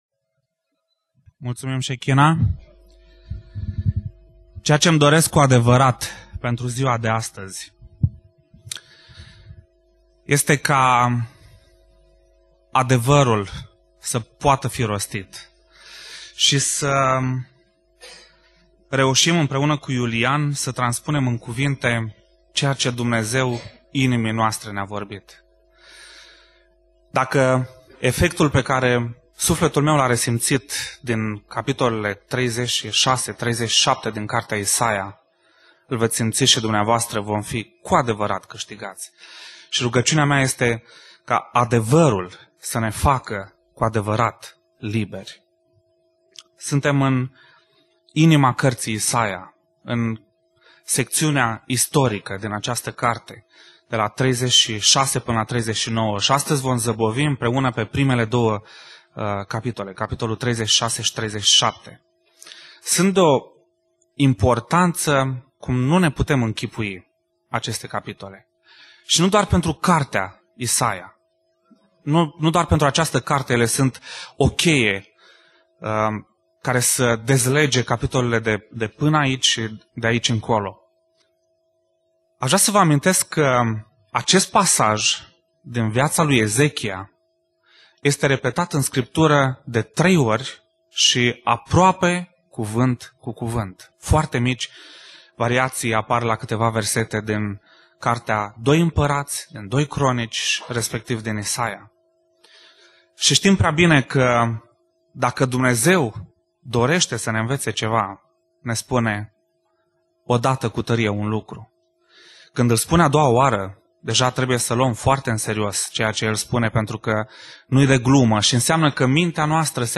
Predica Exegeza - Isaia 36-37